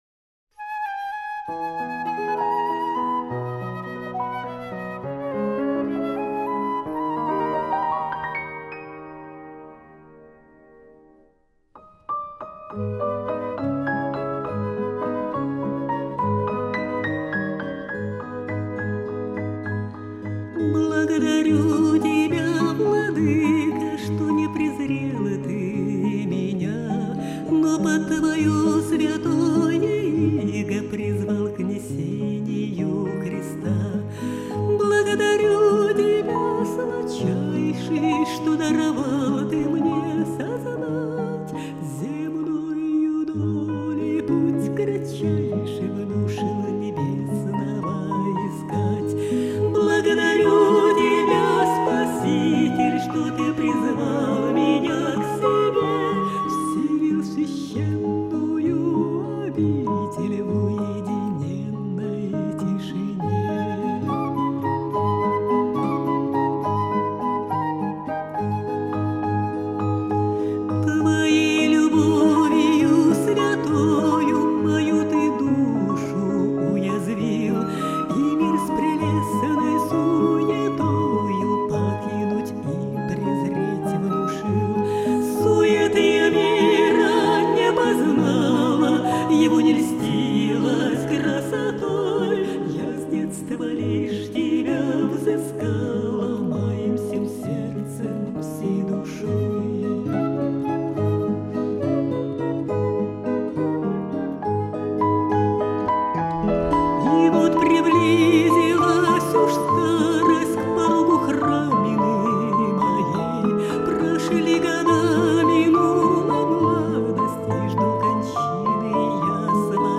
Духовная музыка
Она обладает глубоким лирико - драматическим меццо-сопрано.